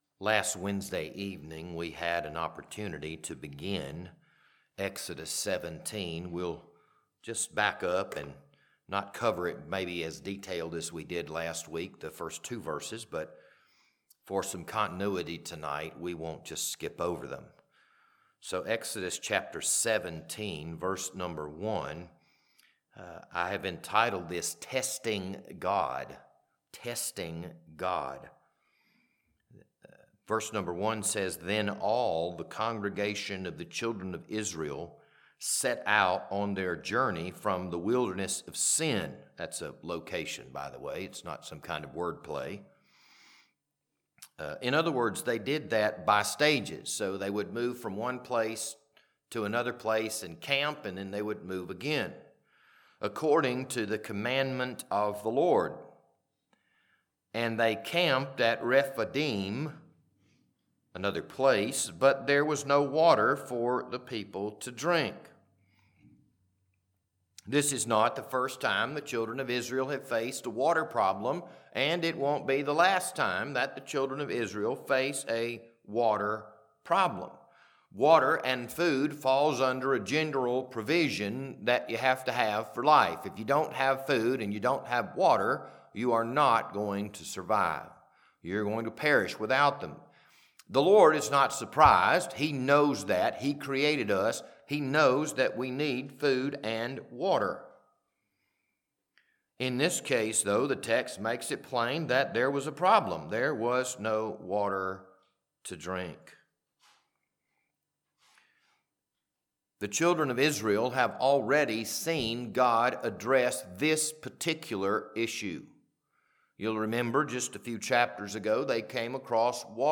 This Wednesday evening Bible study was recorded on March 25th, 2026.